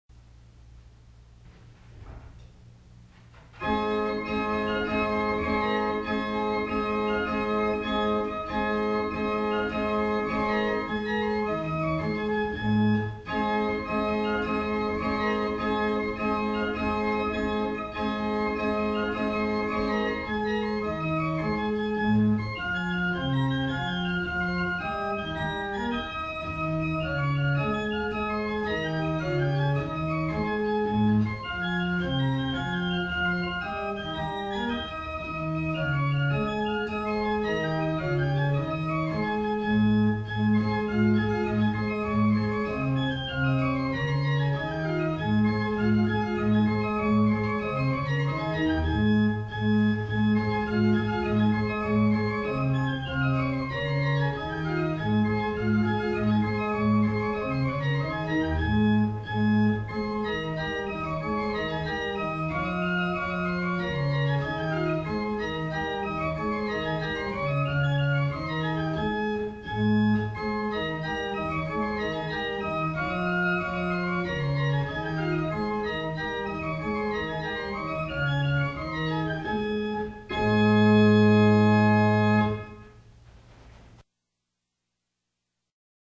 1802 Tannenberg Organ
Hebron Lutheran Church - Madison, VA
Listen to Quadrill or Double Cotillion by Alexander Reinagle played on Gedackt 8', Principal 4' and Octav 2' by clicking